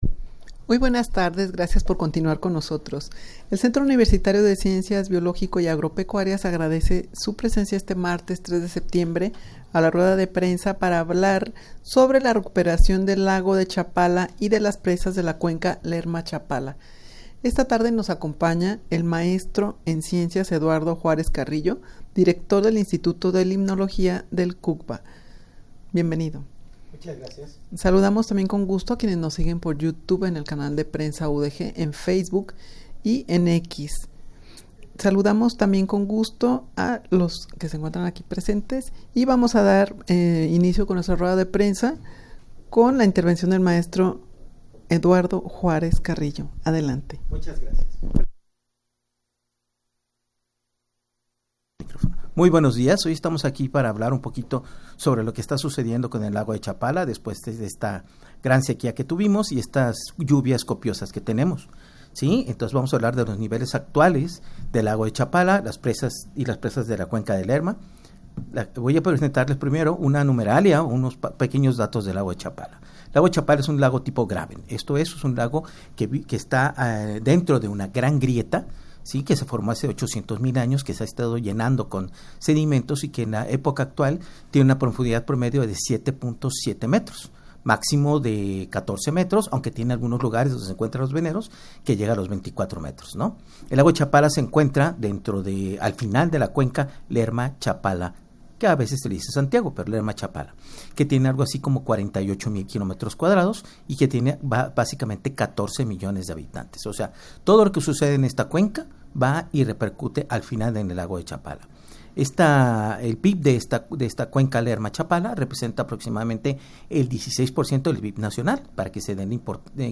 rueda-de-prensa-recuperacion-del-lago-de-chapala-y-de-las-presas-de-la-cuenca-lerma-chapala.mp3